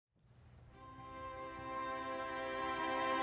4/26/2015 Fenway Center
violin
viola
cello
NUCM-Schubert-Quintet-Mvt-I-excerpt-1.mp3